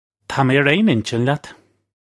Pronunciation for how to say
Tah may air ayn in-chin lyat (U)
This is an approximate phonetic pronunciation of the phrase.